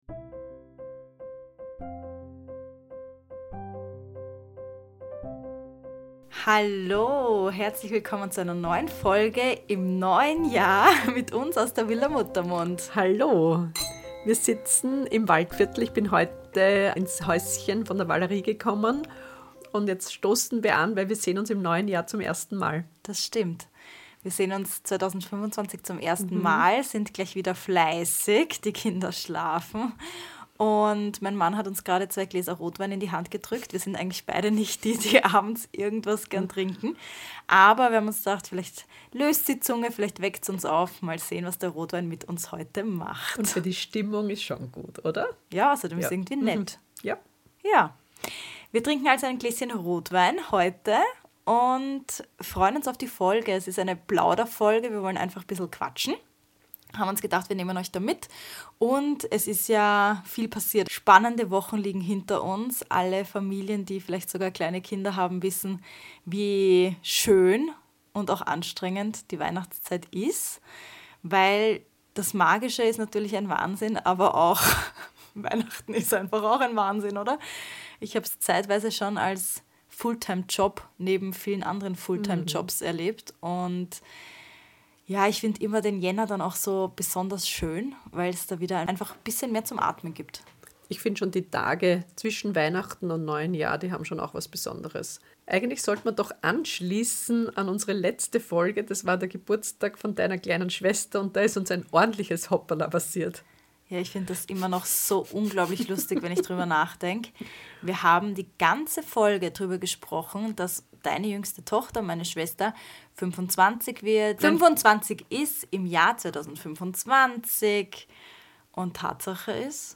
Begleitet sie in dieser Plauderfolge und holt euch die Vorfreude auf 2025!